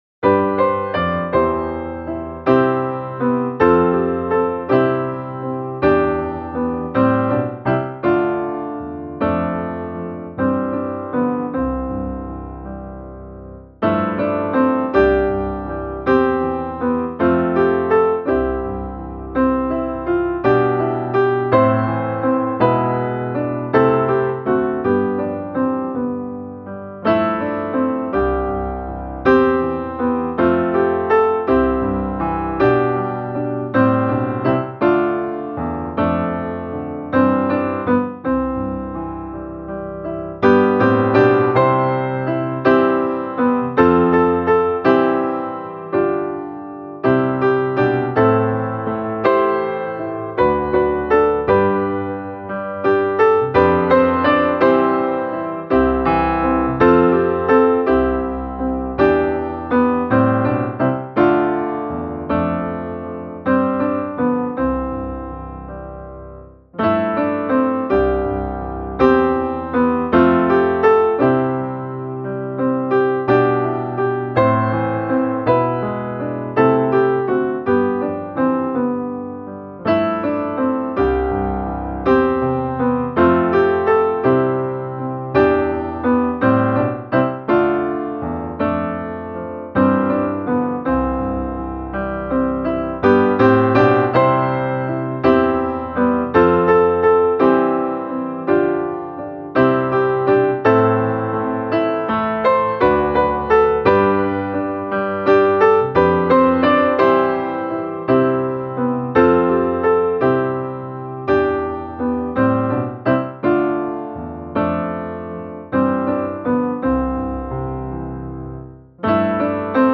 Saliga visshet, Jesus är min - musikbakgrund
Musikbakgrund Psalm